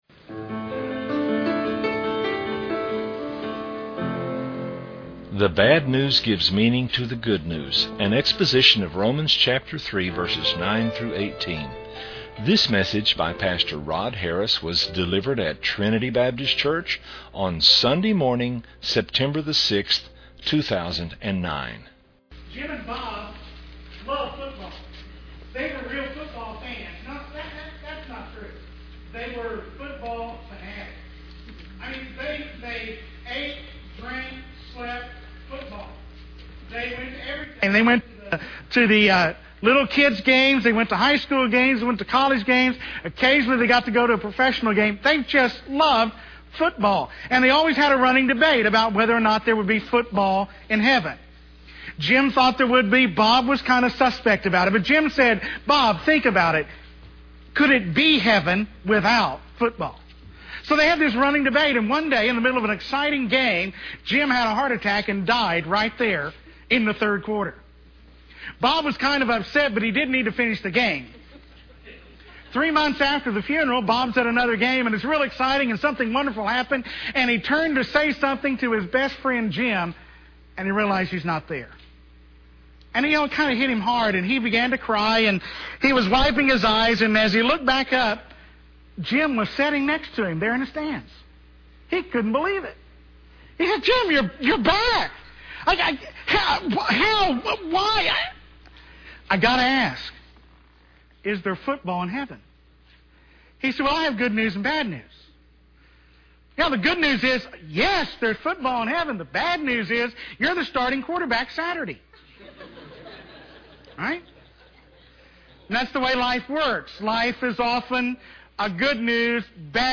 delivered at Trinity Baptist Church on Sunday morning